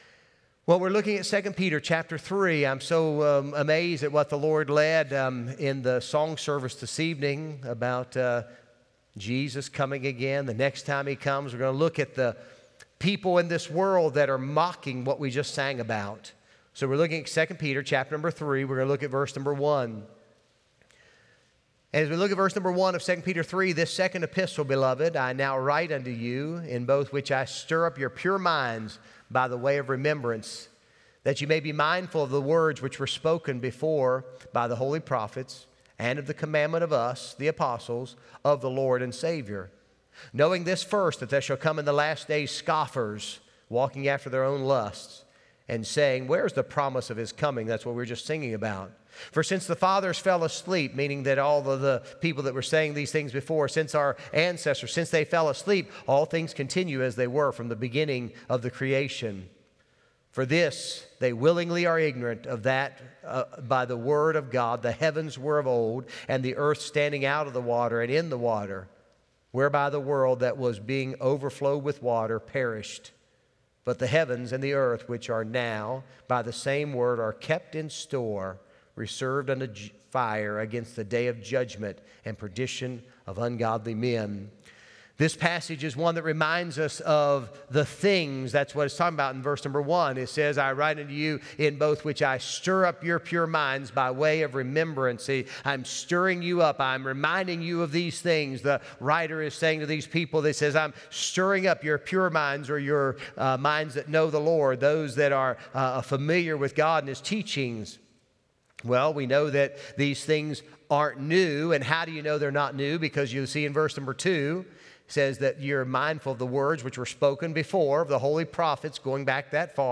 Sunday Evening Service